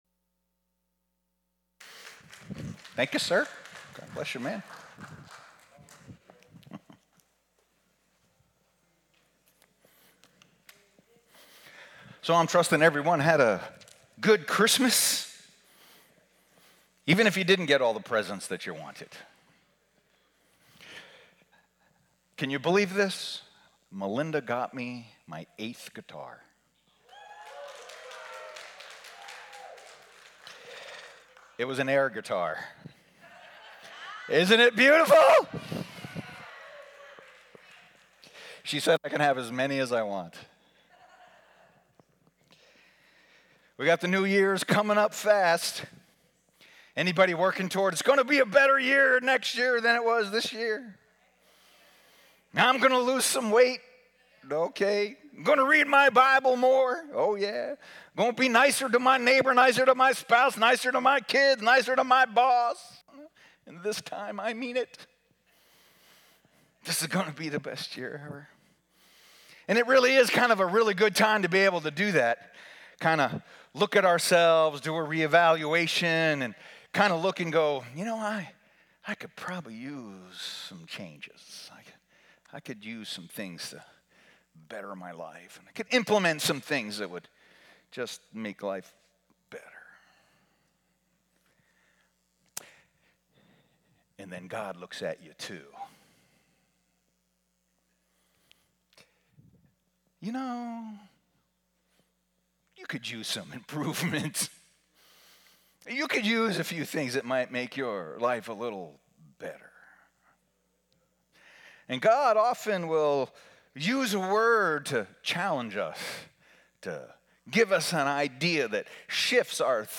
Sermons | New Life Alliance Church